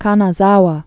(känä-zäwə)